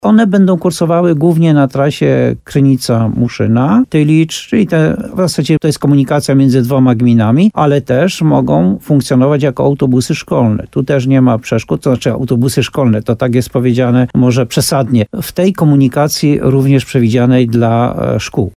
Pierwsze autobusy niskoemisyjne powinny pojawić się w gminie Muszyna jeszcze w październiku – mówi burmistrz Jan Golba. Chodzi o wyłoniony przetarg na cztery duże pojazdy.